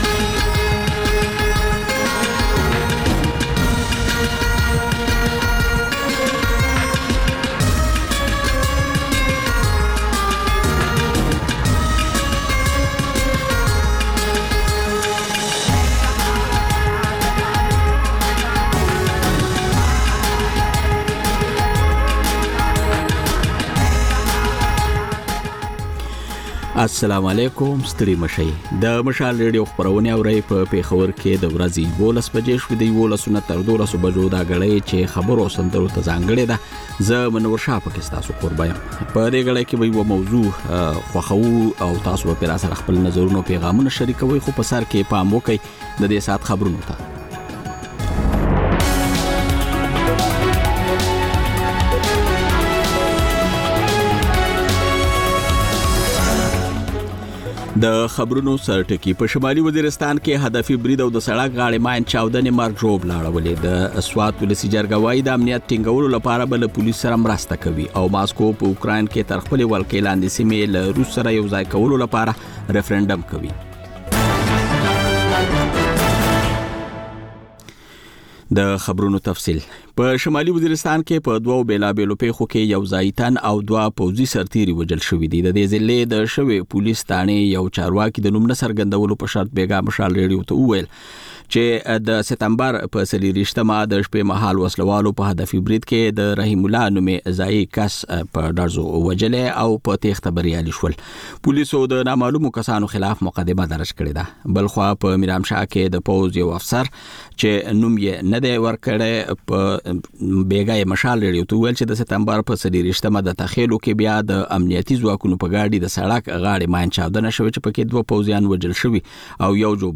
په دې خپرونه کې تر خبرونو وروسته له اورېدونکیو سره په ژوندۍ بڼه خبرې کېږي، د هغوی پیغامونه خپرېږي او د هغوی د سندرو فرمایشونه پوره کول کېږي.